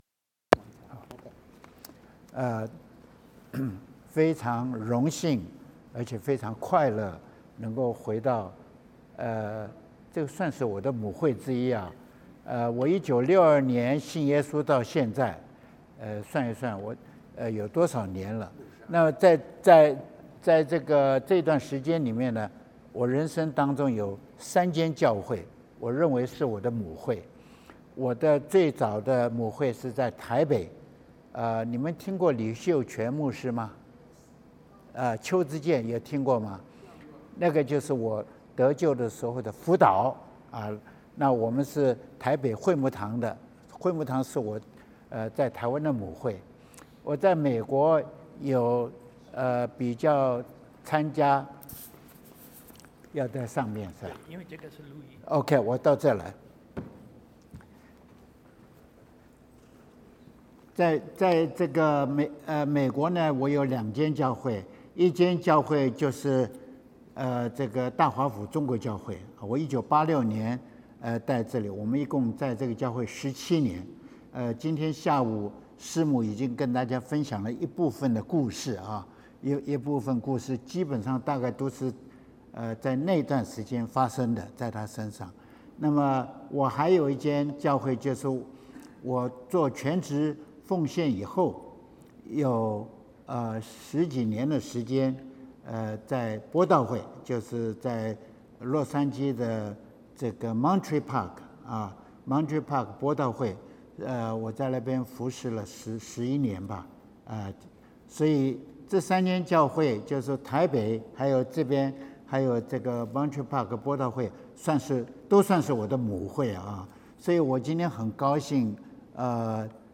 Chinese Sermons | Chinese Christian Church of Greater Washington DC (en)